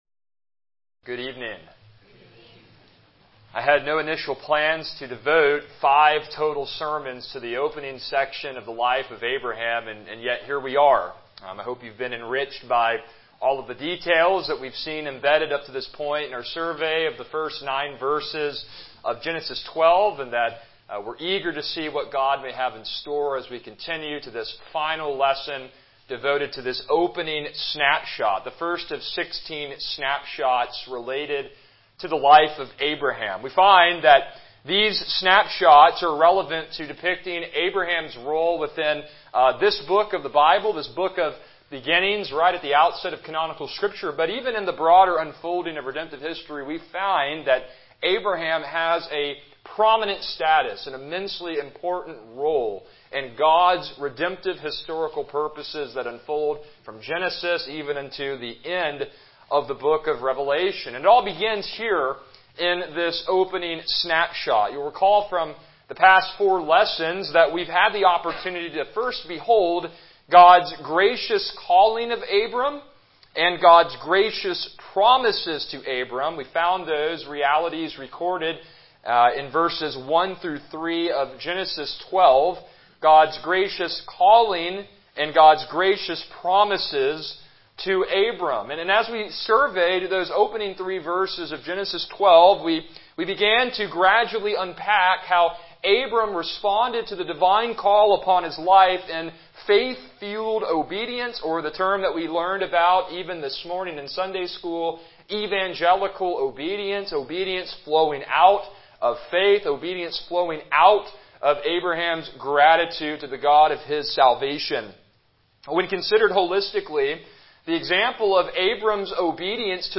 Passage: Genesis 12:7b-9 Service Type: Evening Worship